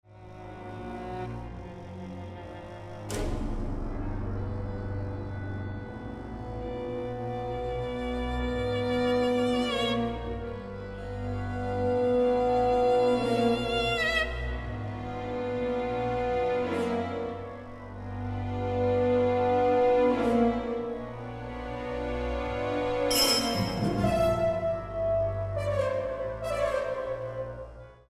Genre: Classical / Halloween
for Solo Viola and Orchestra
Solo Viola and Solo Violin played and recorded by
Virtual Orchestra produced